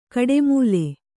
♪ kaḍemūle